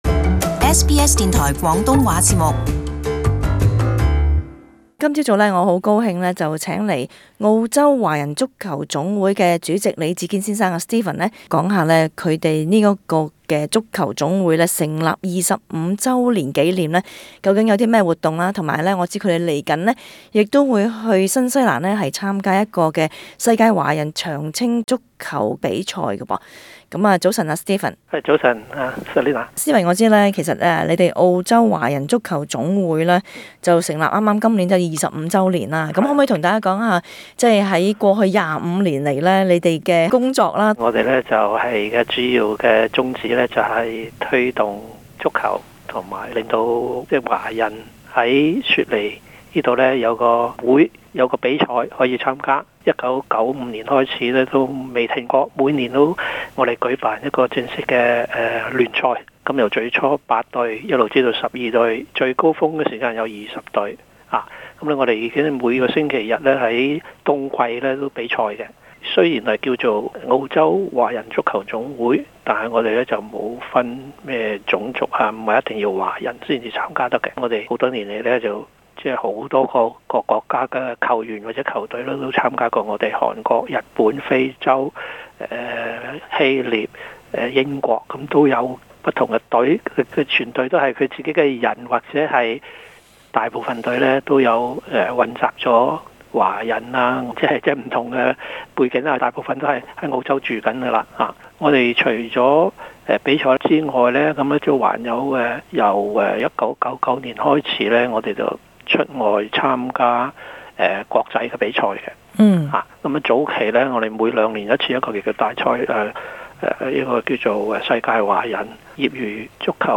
【社区专访】澳洲华人足球总会庆祝成立25周年